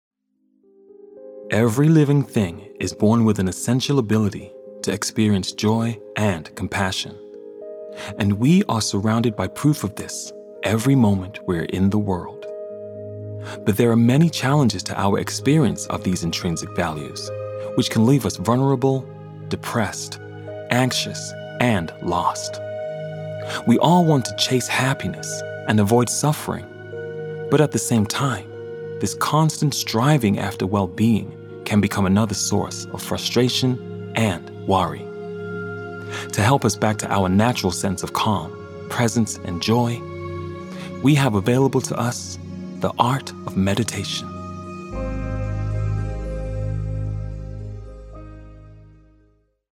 • Native Accent: London